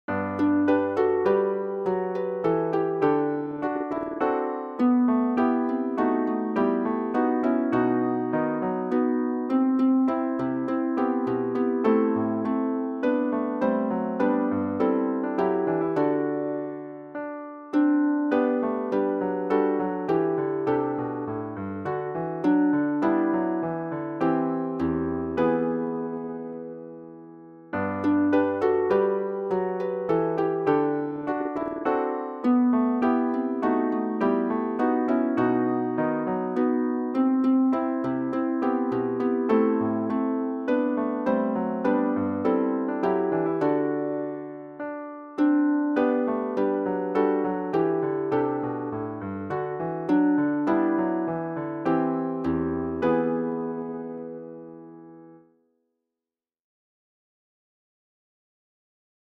Hymne à refrain